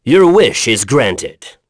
Lusikiel-Vox_Victory.wav